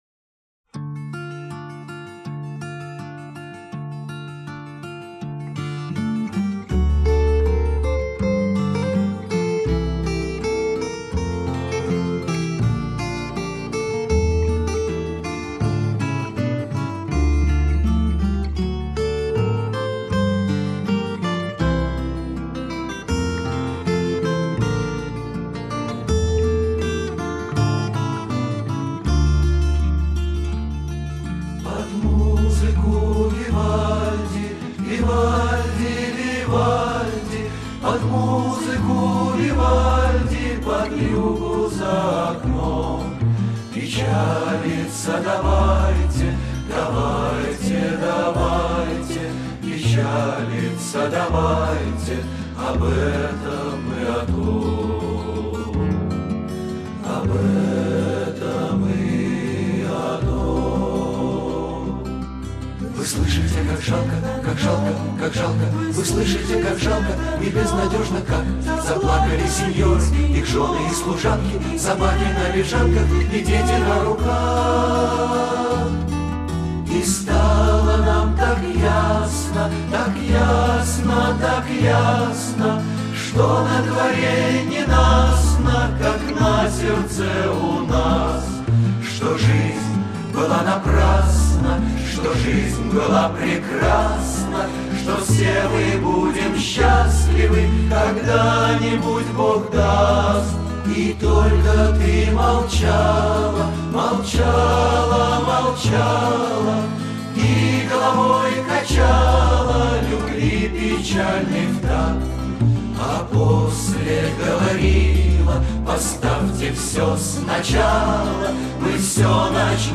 У нас в гостях композитор-песенник